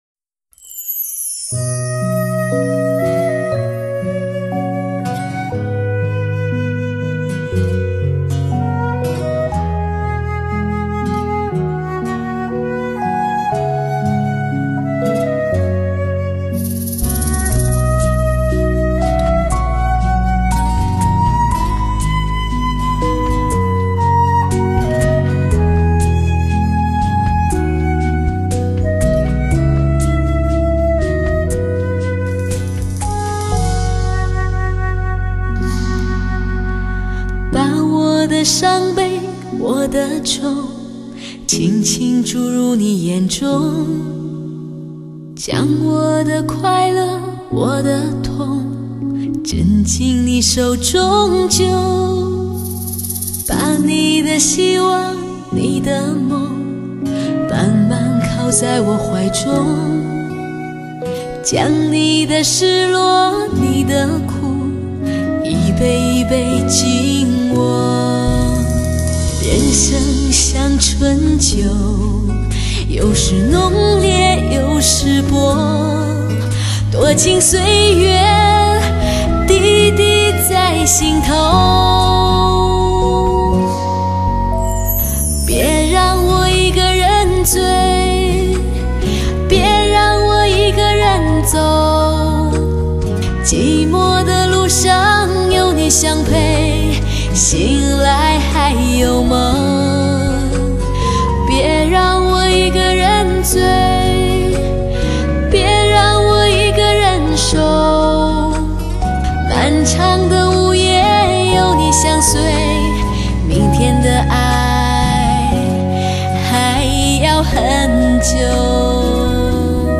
碟碟天籁之声，洗耳沁心。致力于有限空间实现最佳音效。